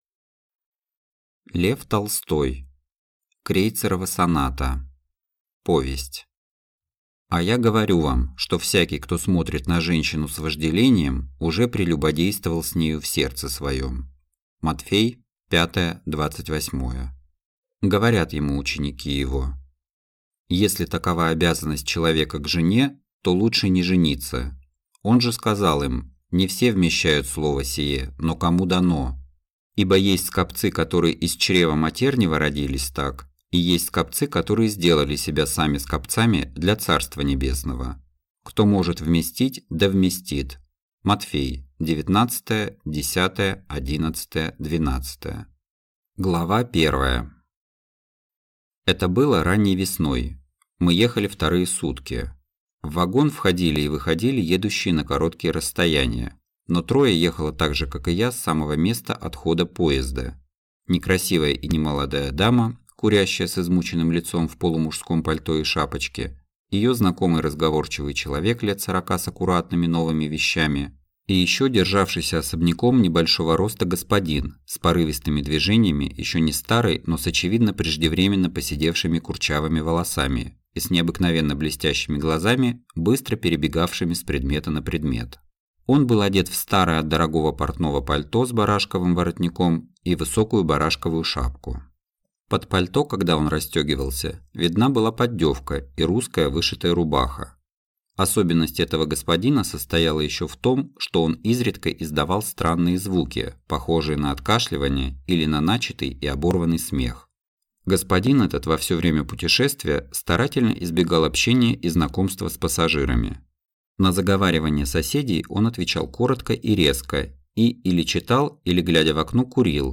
Аудиокнига Крейцерова соната (сборник) | Библиотека аудиокниг
Прослушать и бесплатно скачать фрагмент аудиокниги